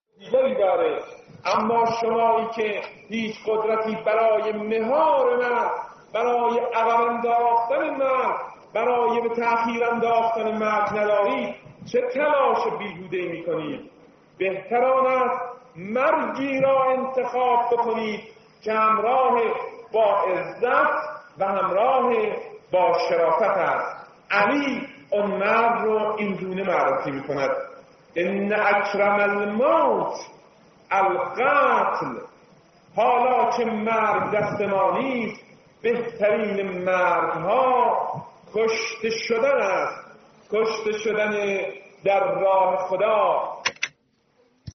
بخشی از سخنرانی شهید سید حسن شاهچراغی درباره مرگ از دیدگاه مولای متقیان علی (ع) که در لیالی قدر سال ۱۳۶۴ در مسجد تاریخانه دامغان ایراد شده را از نظر می‌گذرانید.